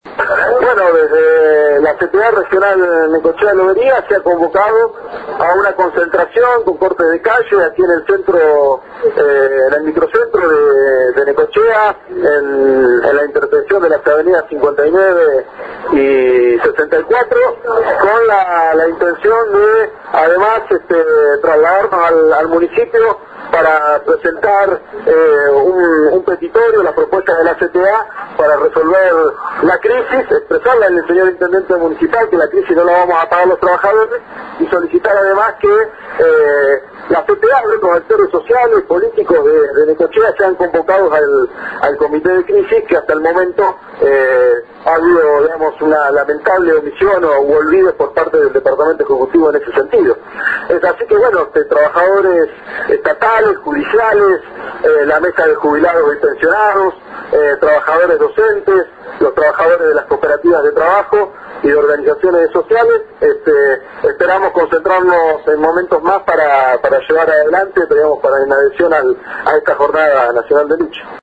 Se est� llevando a cabo una concentraci�n con corte de calle en el microcentro de Necochea, en la intersecci�n de las avenidas 59 y 74 para hacerle llegar al intendente que la crisis no la vamos a pagar los trabajadores y solicitar adem�s la convocatoria al comit� de crisis, que hasta el momento el ejecutivo municipal ha omitido.